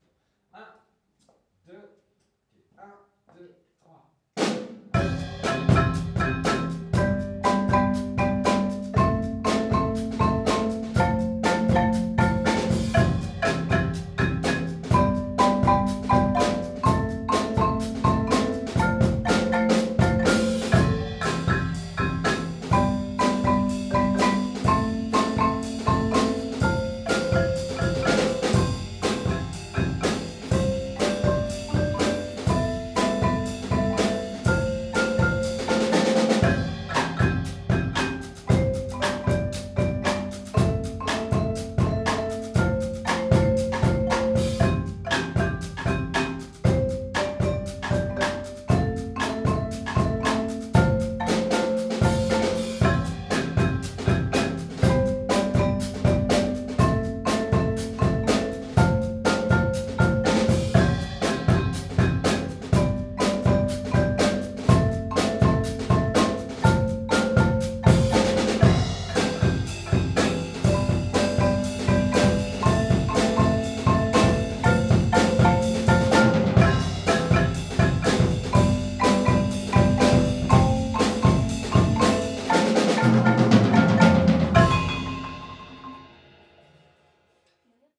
Rythmique Punk
L'accompagnement audio de votre compo